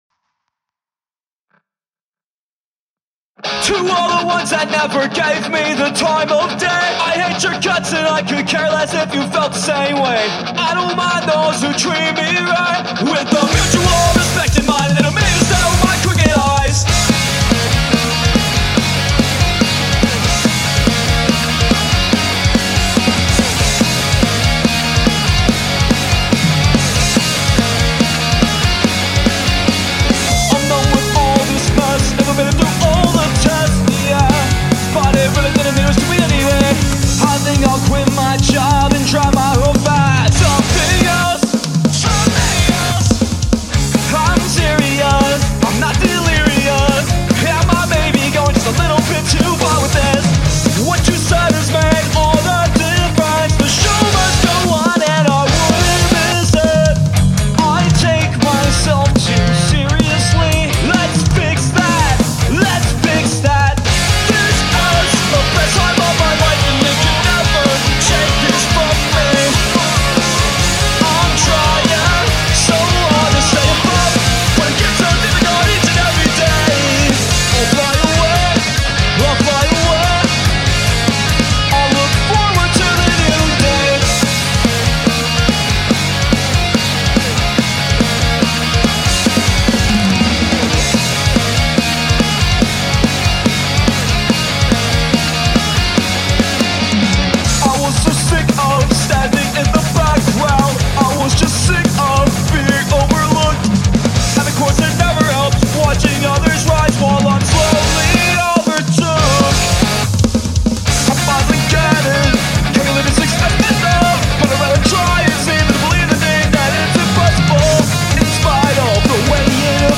So here's an encouraging, if slightly angsty, pop-punk jam!